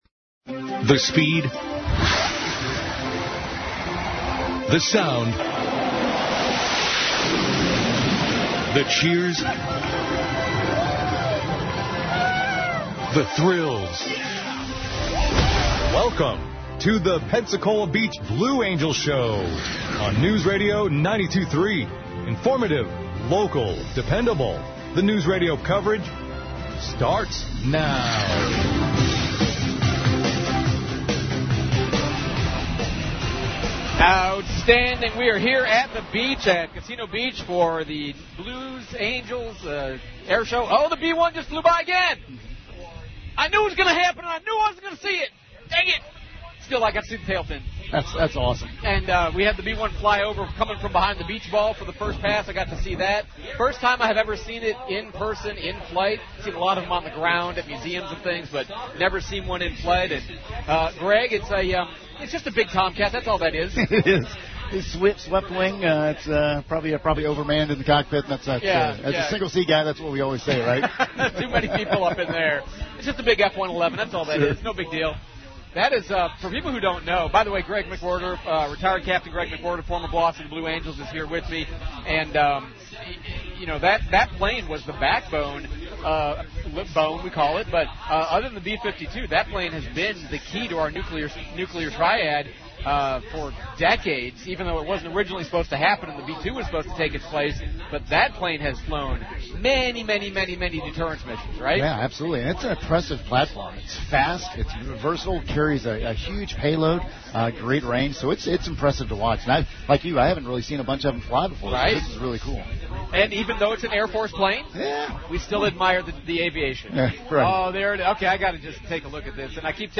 Pensacola Beach Airshow